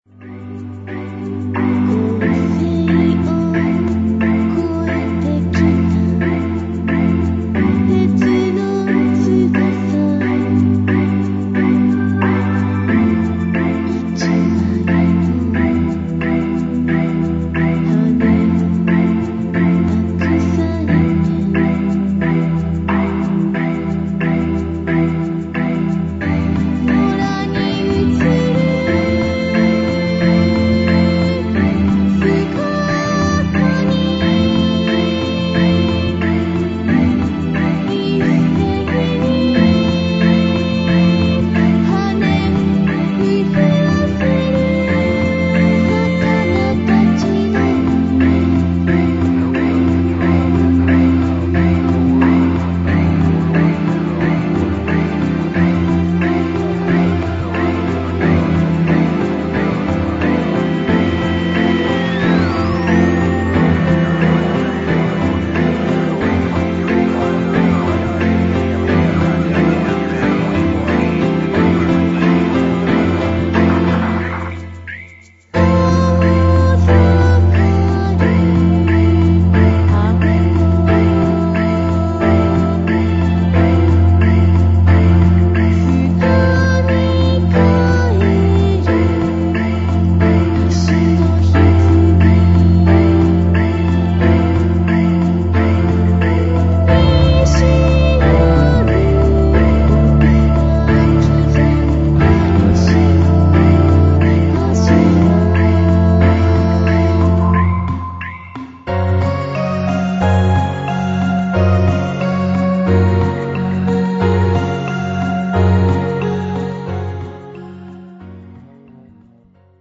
電脳空間を浮遊する女声Voが物語る博物誌